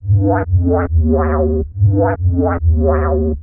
S Vib Bass
描述：低音VST合成器，合唱。
标签： 140 bpm Dubstep Loops Bass Loops 590.67 KB wav Key : Unknown
声道立体声